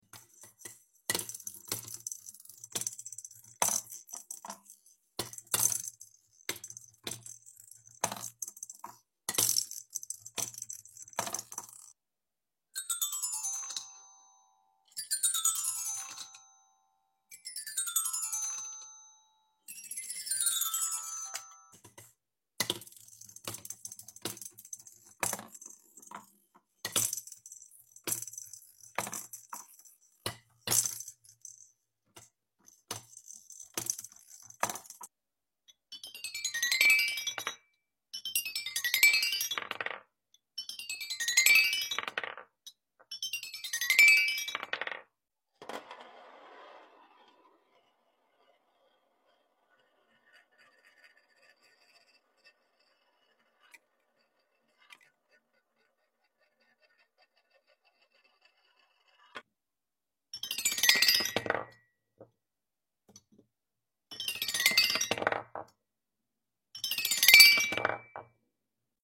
Hammering Fun Tower | ASMR Sound Effects Free Download